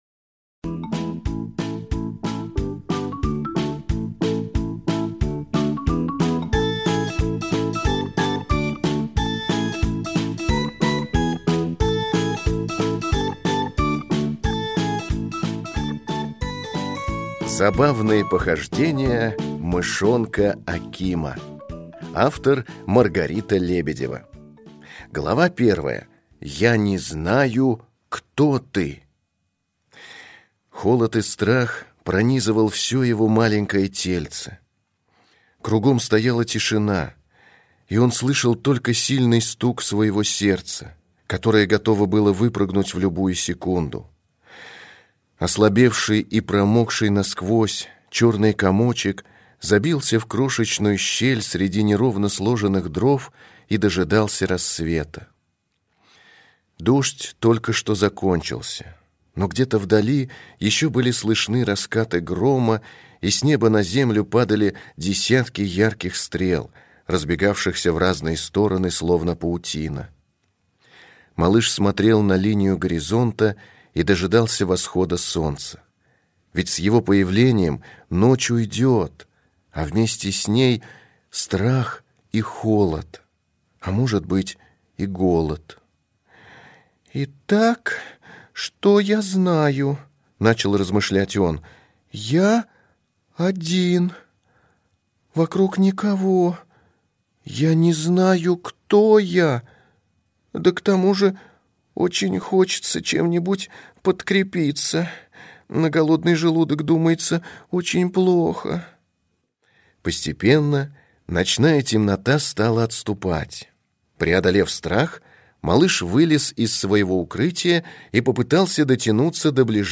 Аудиокнига Забавные похождения мышонка Акима | Библиотека аудиокниг